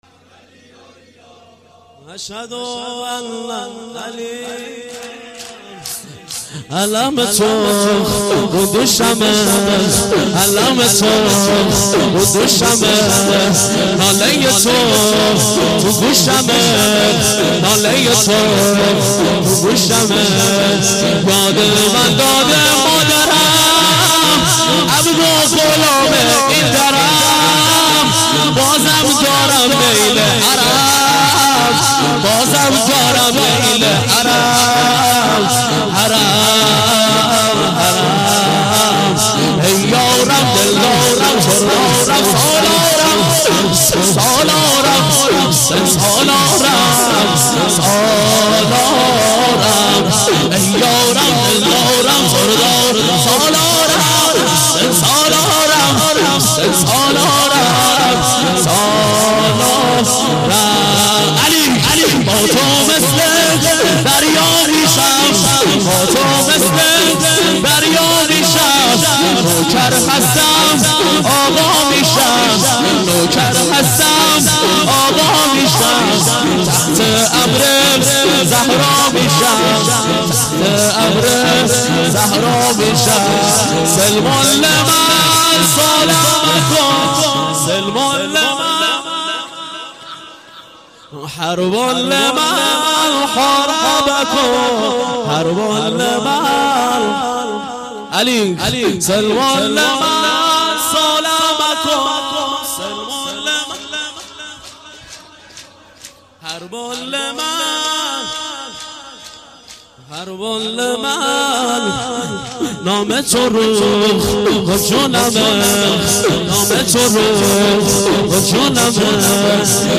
شور - علم تو رو دوشم - مداح